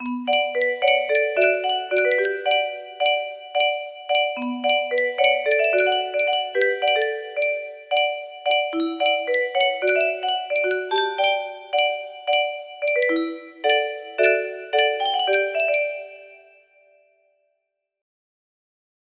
BPM 110